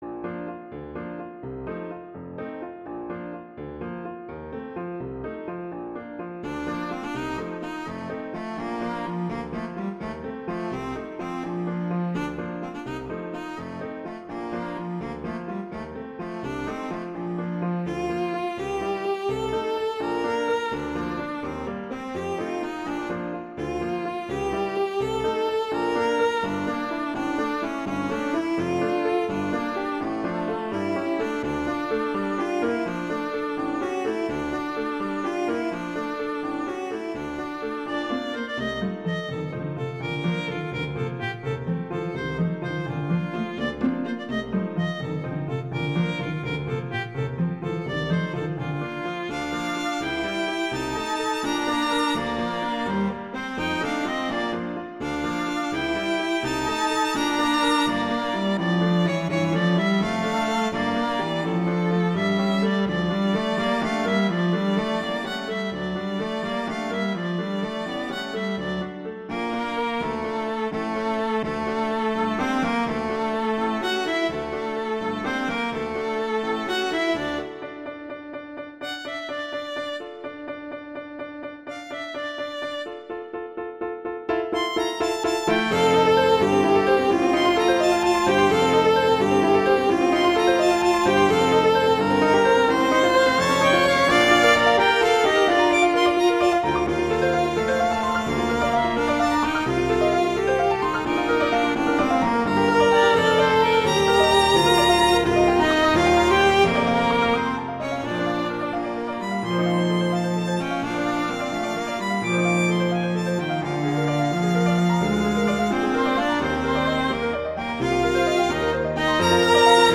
classical, french
Bb major
♩. = 84 BPM